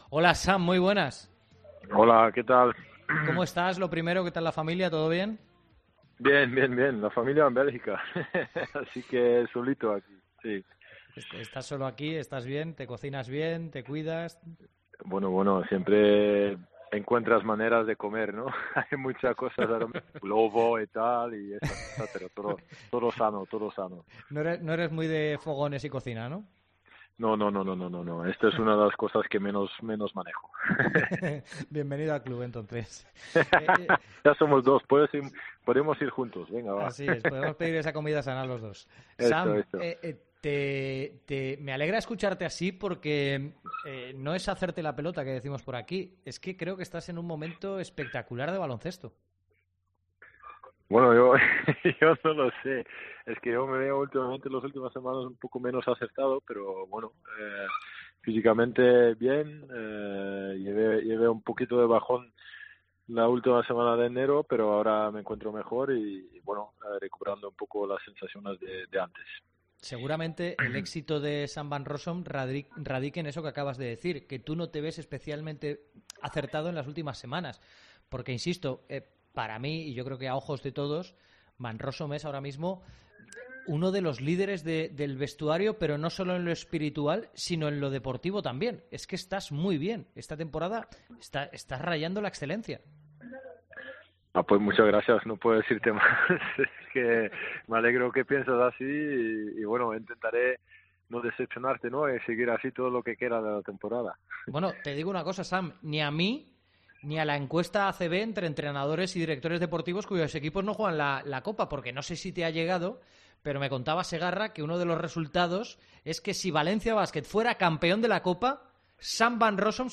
AUDIO. Entrevista a Sam Van Rossom en COPE